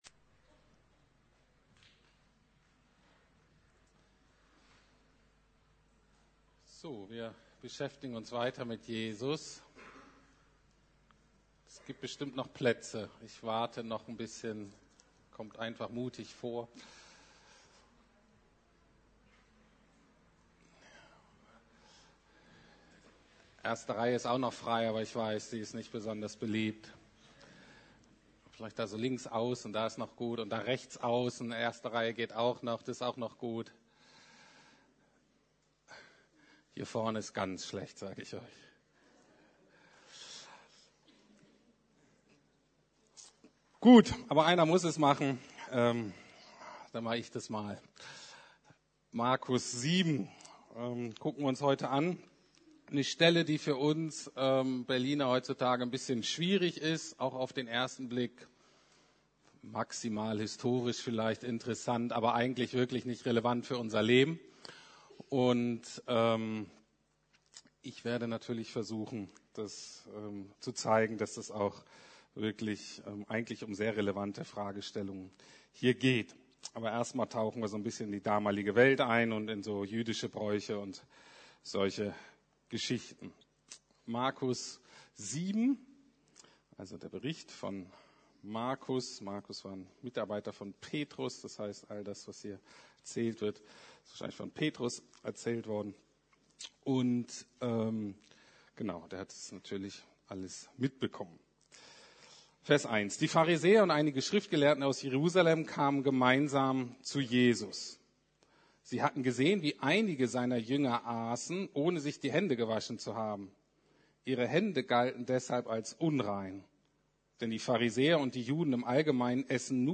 Faszination Jesus: Veränderung von außen nach innen oder von innen nach außen? ~ Predigten der LUKAS GEMEINDE Podcast